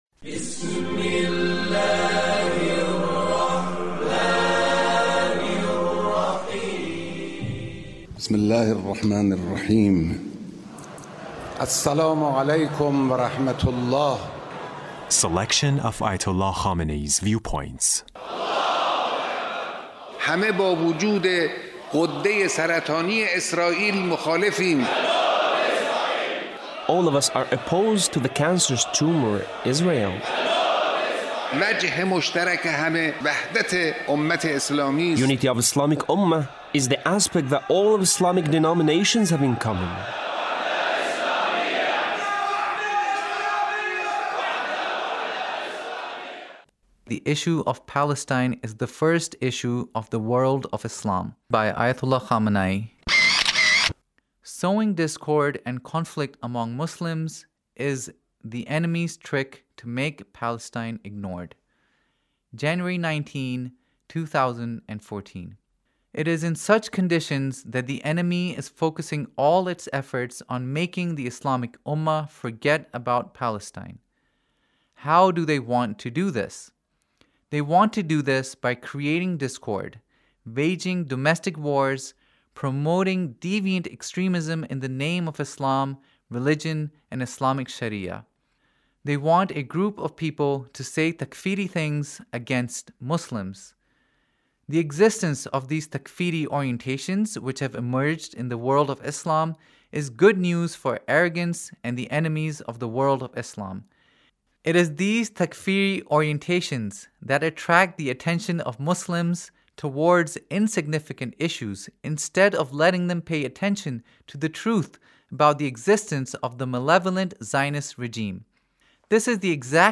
Leader's Speech (1866)